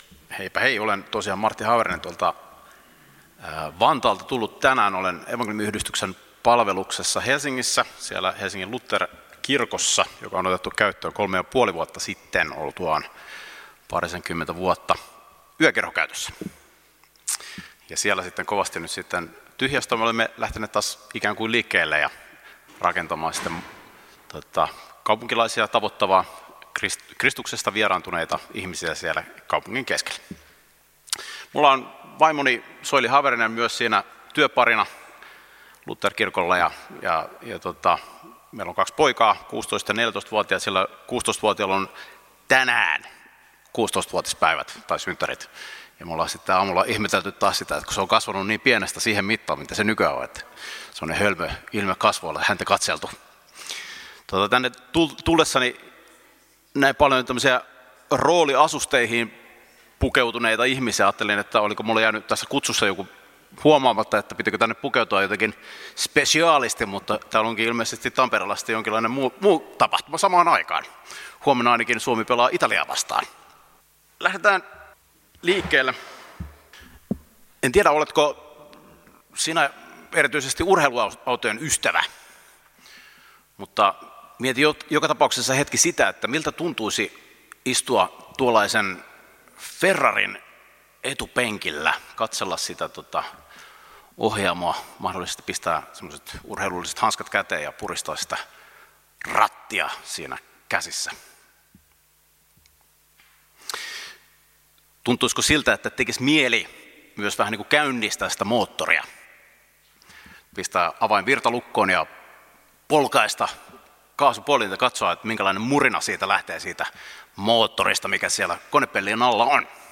Kokoelmat: Tampereen evankeliumijuhlat 2019